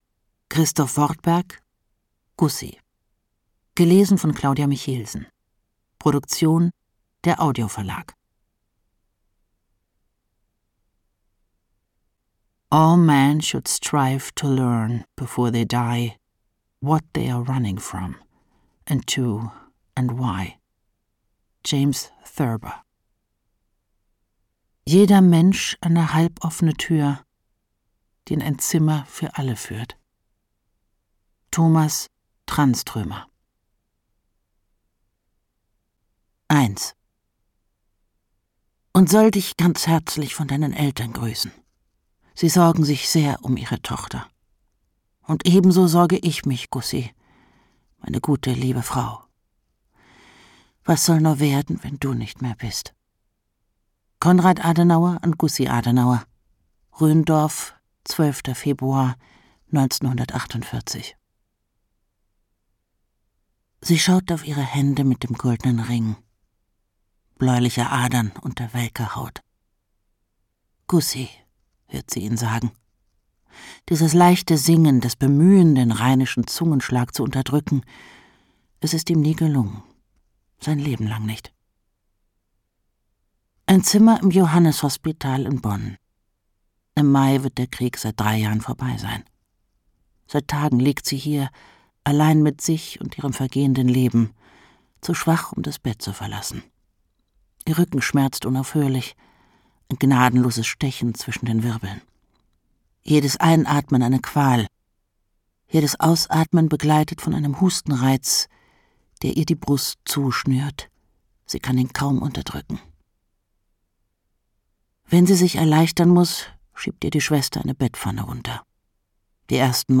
Ungekürzte Lesung mit Claudia Michelsen (1 mp3-CD)
Claudia Michelsen (Sprecher)